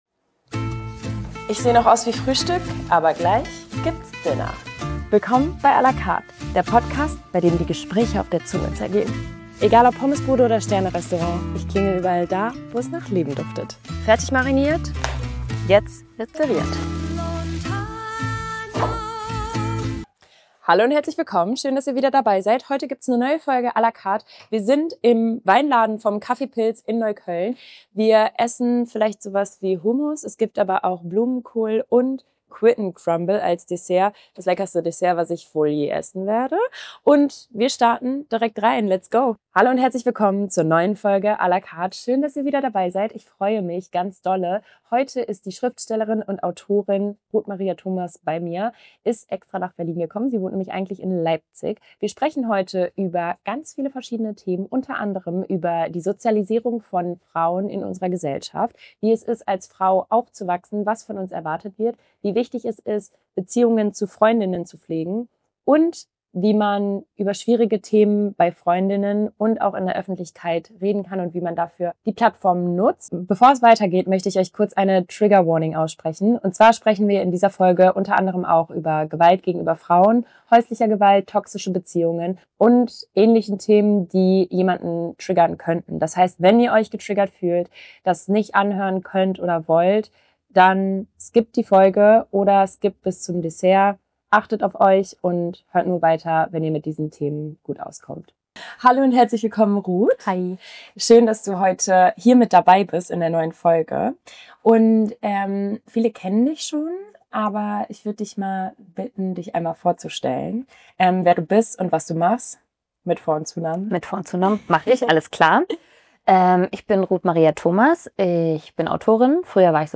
im Café Pilz